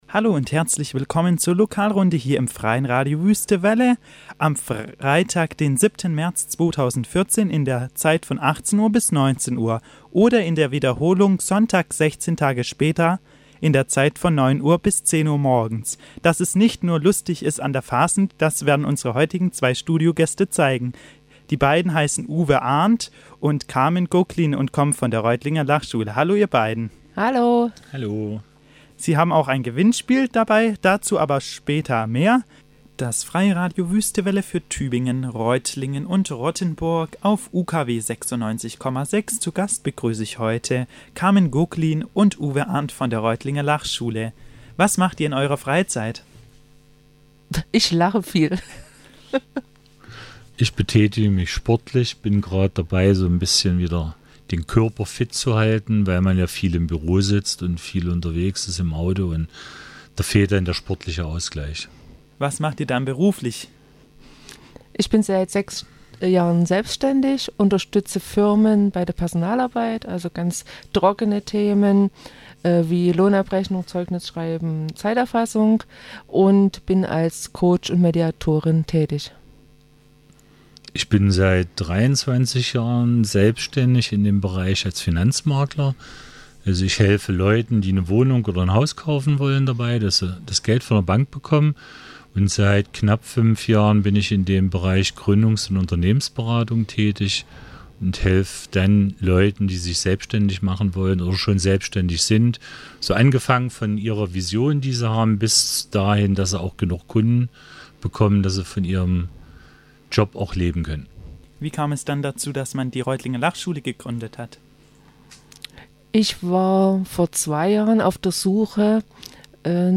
Lustig ging es zu in der LokalRunde im März